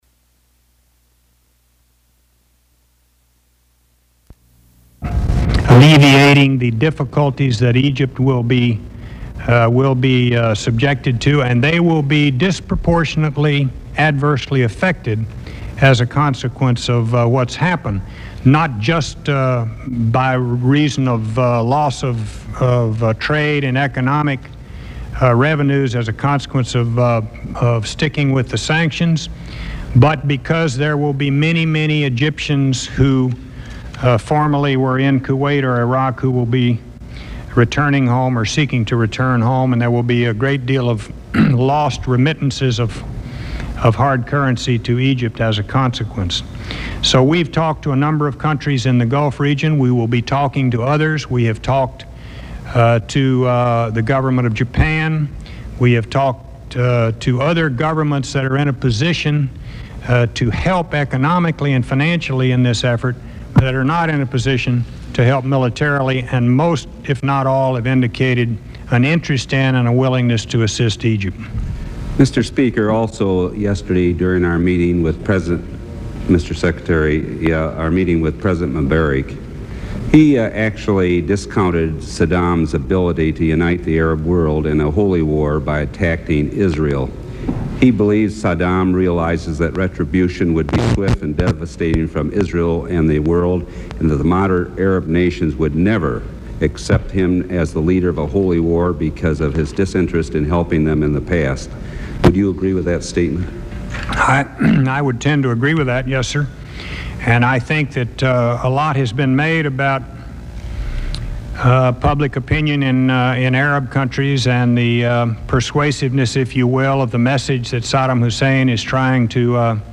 Secretary of State James Baker speaks to the House Foreign Affairs Committee on the events leading up to the Iraqi invasion of Kuwait